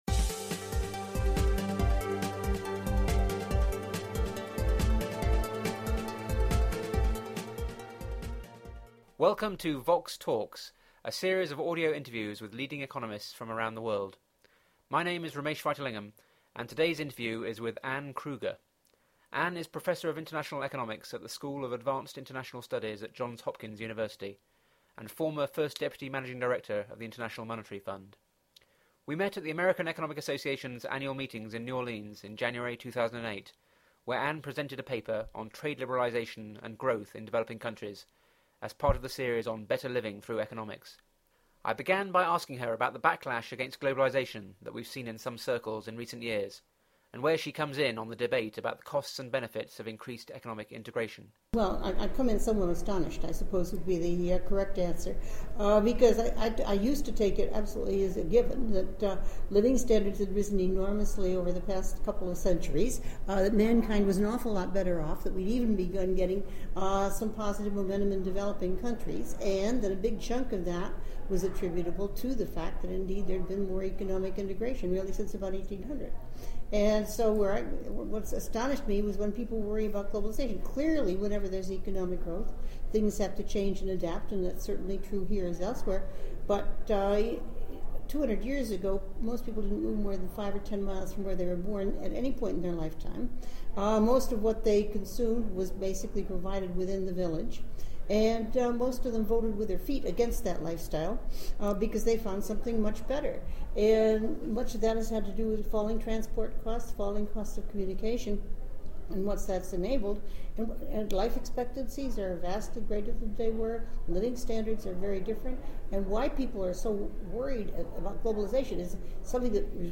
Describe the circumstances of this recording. The interview was recorded at the American Economic Association meetings in New Orleans in January 2008.